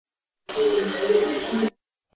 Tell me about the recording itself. The raw but slightly amplified EVP